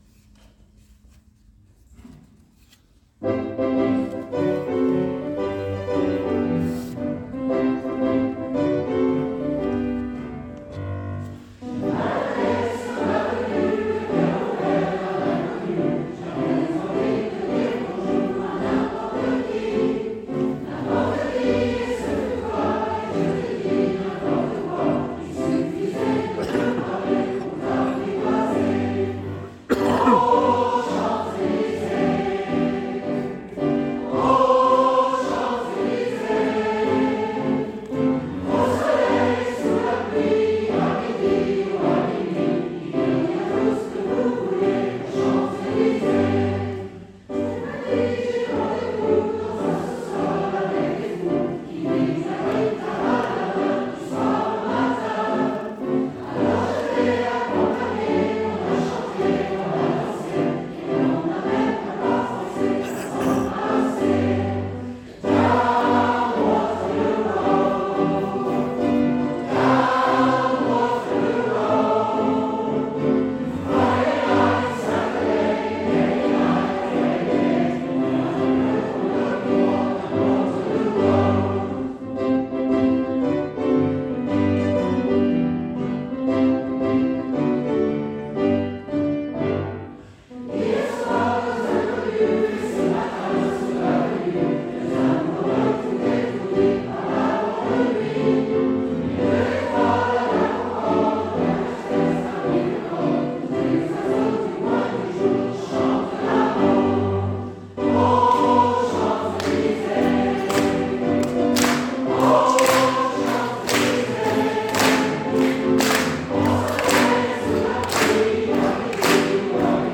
The following audio recordings are snippets from previous concerts to give you a taste of our repertoire